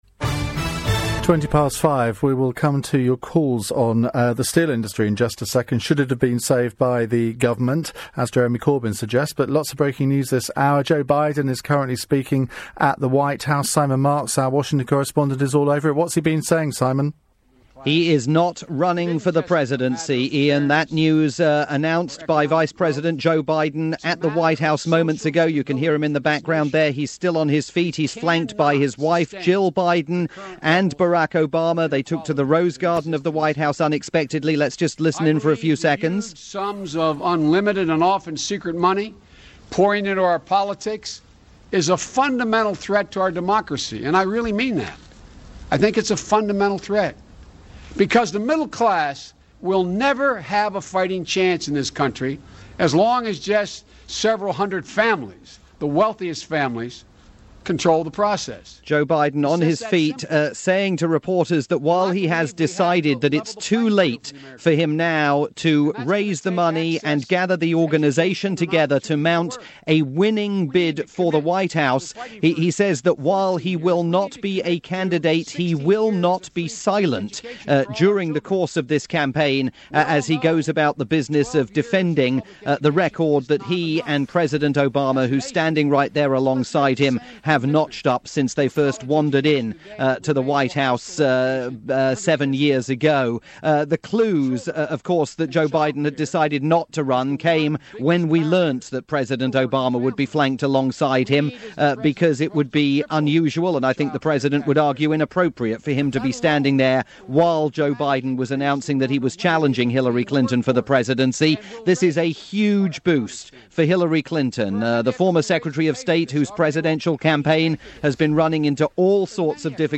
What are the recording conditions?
reported the news, as it happened, on Britain's LBC.